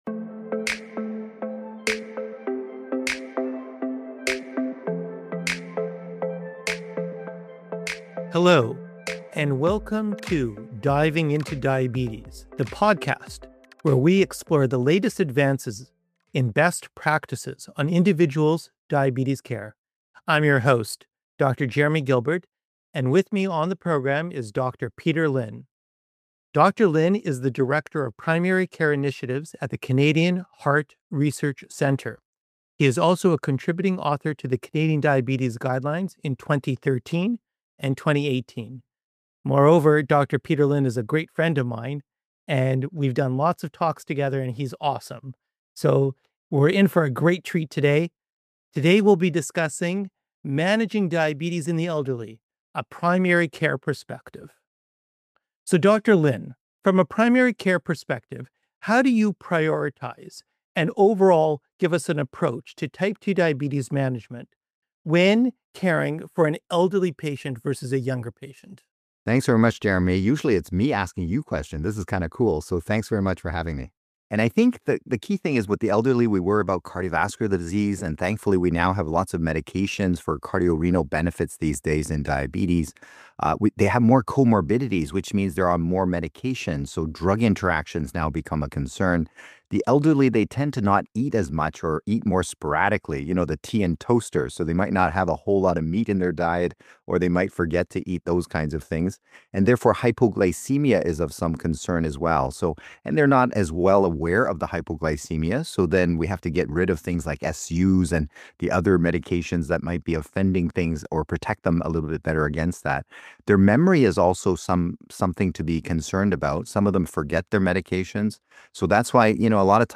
The world of diabetes management is constantly evolving, with new research, new treatments, and new recommendations for best practice. Diving into Diabetes is a series of in-depth conversations with experts who are on the front lines of diabetes research and management.